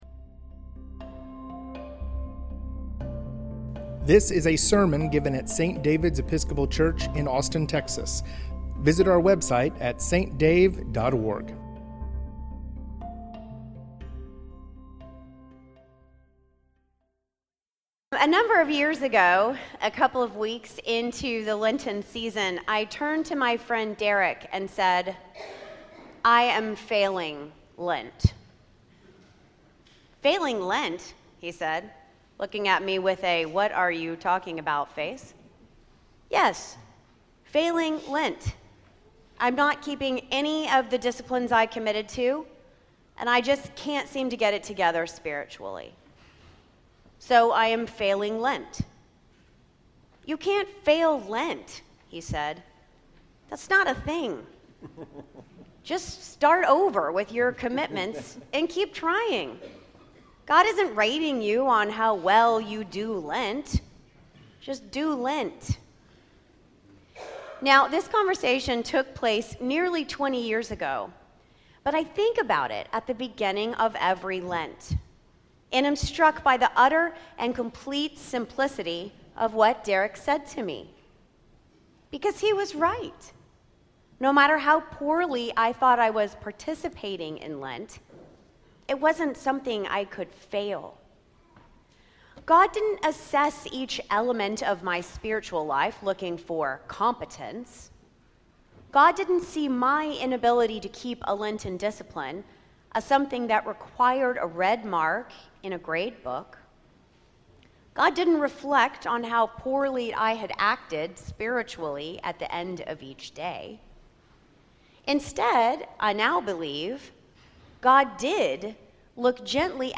The Abbey at St. David's Sermon